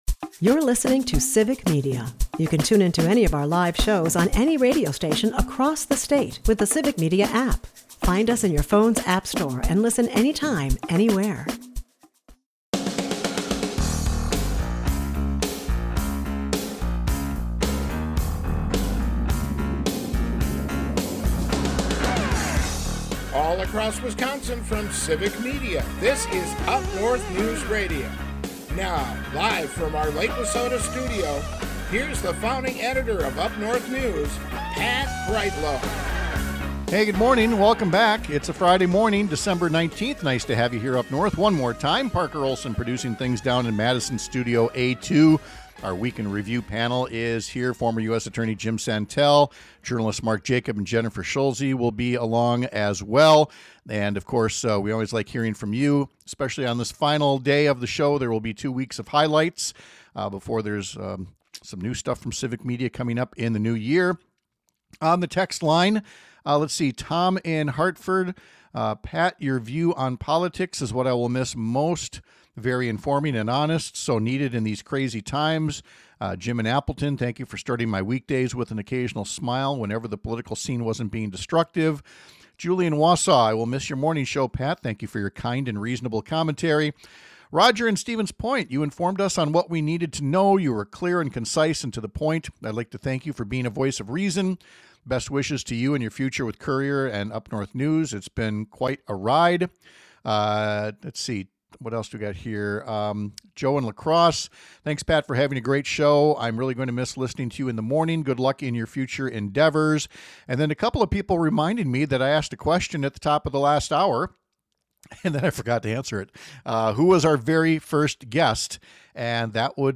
We’ll have one more go-round with our Week In Review panel and ask them “what have we learned” in 2025 — and how can we take those lessons and apply them toward getting our country back on track in 2026.